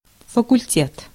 Ääntäminen
France: IPA: [fa.kyl.te]